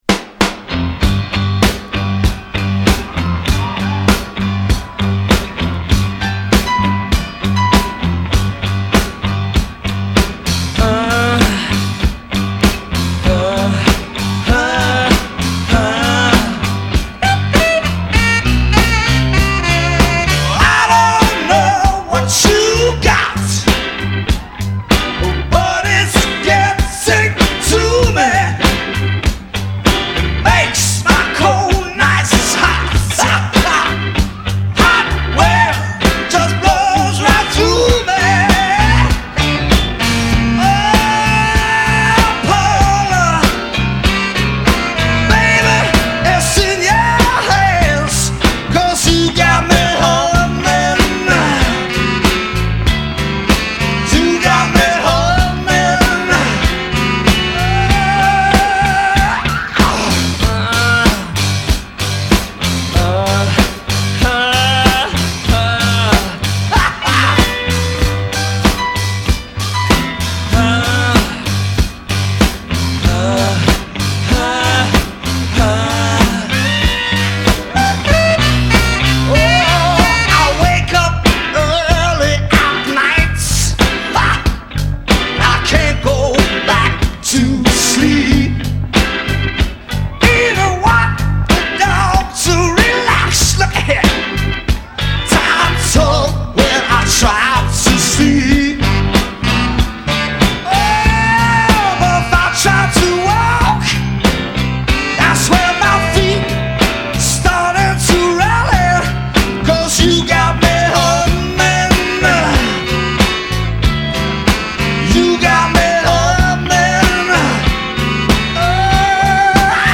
live cover